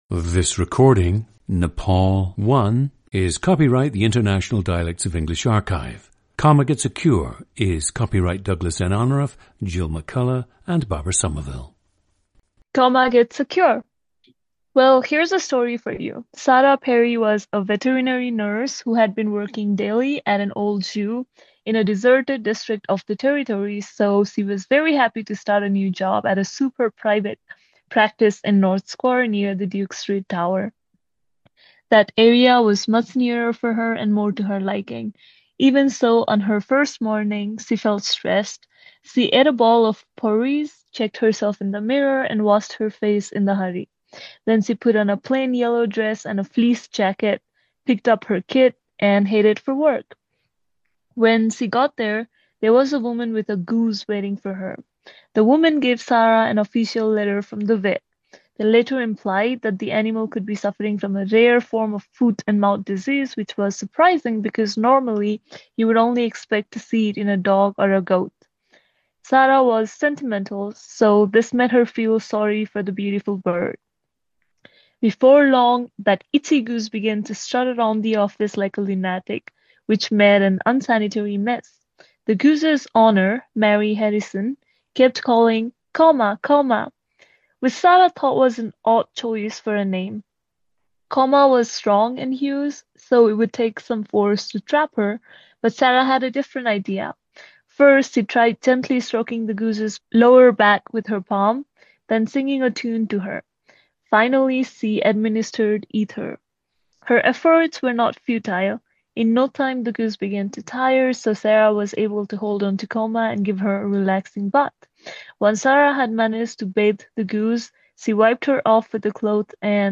GENDER: female
Also likely contributing to her very light accent is her involvement in public speaking and acting.
ORTHOGRAPHIC TRANSCRIPTION OF UNSCRIPTED SPEECH:
• Recordings of accent/dialect speakers from the region you select.